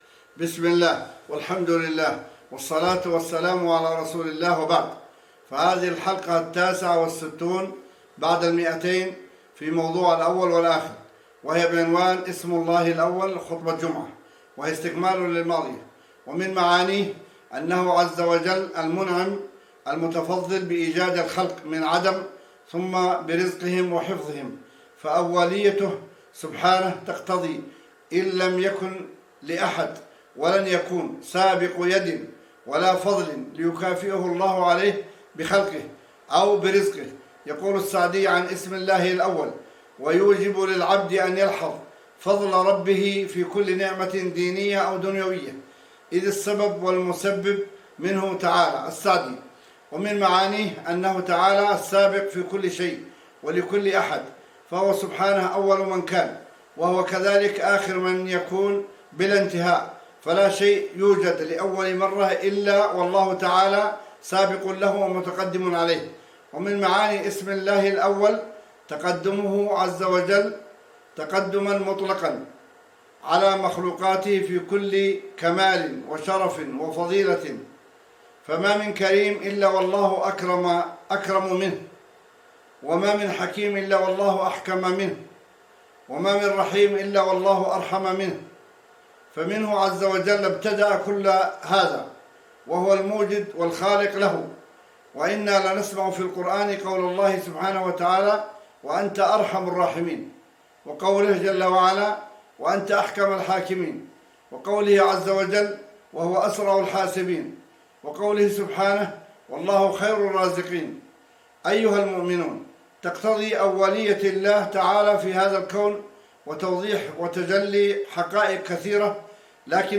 بعنوان :* اسم الله الأول ( خطبة جمعة )